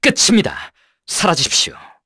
voices / heroes / kr
Zafir-Vox_Skill7_kr.wav